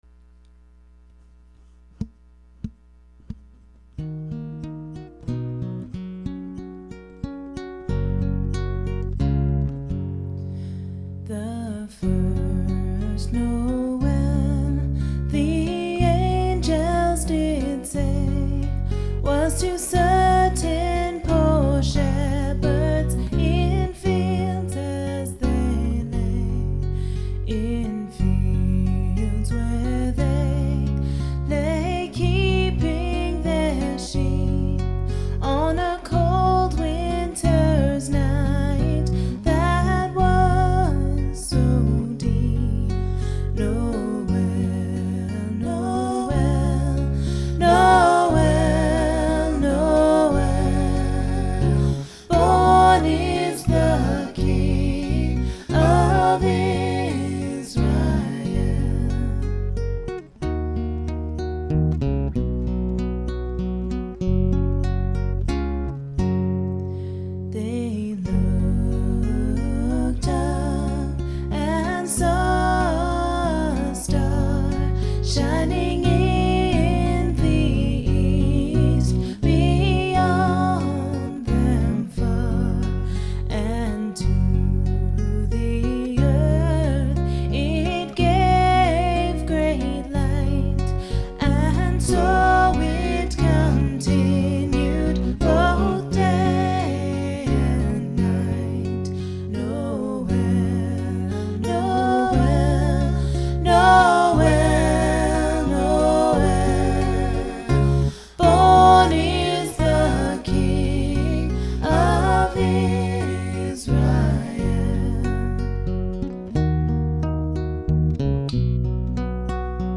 For those of you still in the Christmas spirit for one reason or another, here are a couple of carols dad and I have put together over the last couple of years!